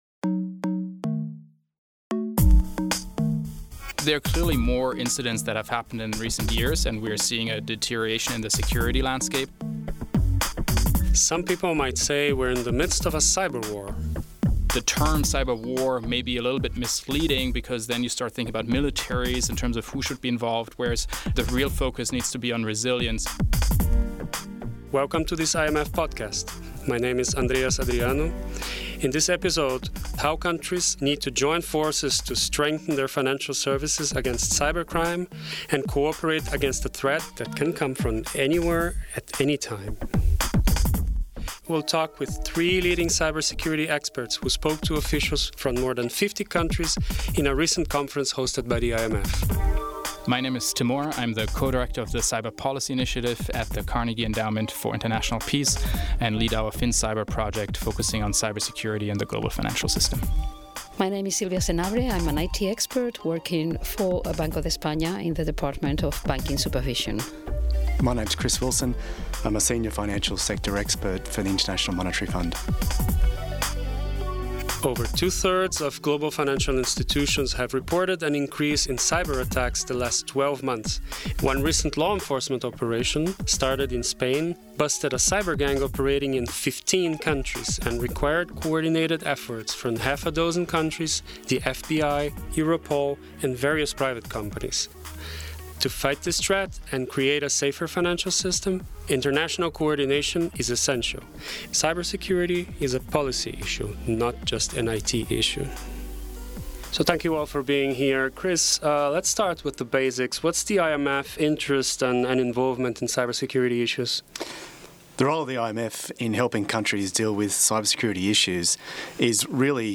In this podcast, three leading specialists in cybersecurity discuss how to create a safer digital world for financial institutions.